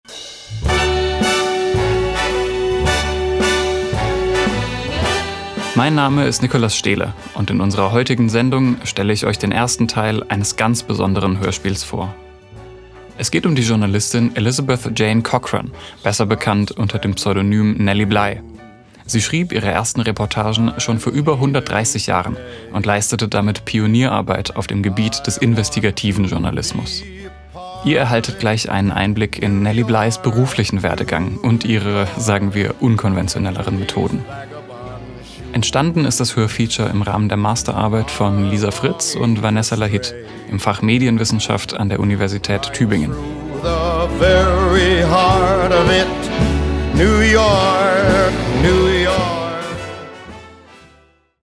Die Beteiligten und ihre Rollen: